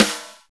SNR R8 RIM 1.wav